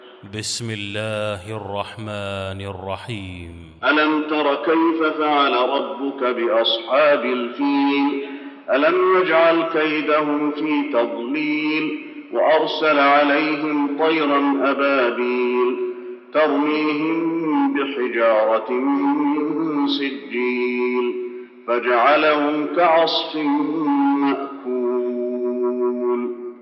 المكان: المسجد النبوي الفيل